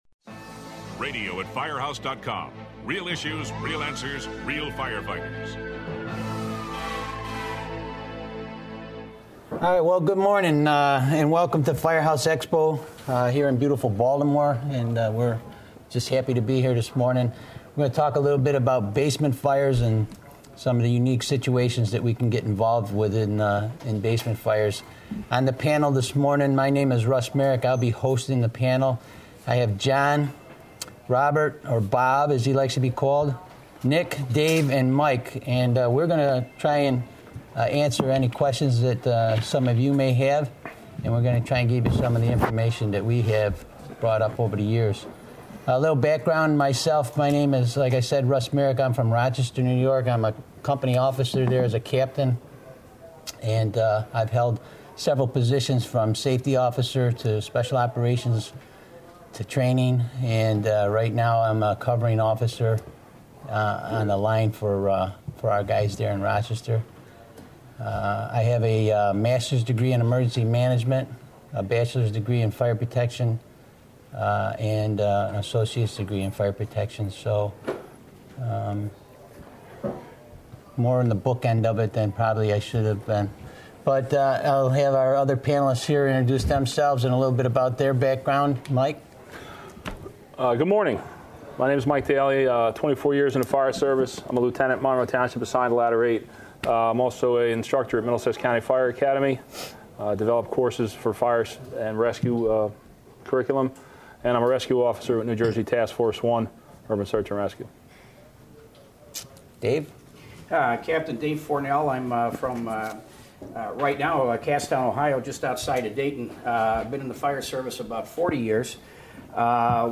Basement Fire Tactics Roundtable at Firehouse Expo
Listen as veteran firefighters from large and small departments discuss the dangers involved with below grade fires.
This group of firefighters and officers from along the East Coast look at the common and hidden dangers found at basement fires.
This podcast was recorded at Firehouse Expo in July.